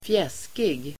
Ladda ner uttalet
Folkets service: fjäskig fjäskig adjektiv, fawning Uttal: [²fj'es:kig] Böjningar: fjäskigt, fjäskiga Definition: inställsam, tillgjord officious adjektiv, beskäftig , tjänstvillig , mycket tjänstvillig , fjäskig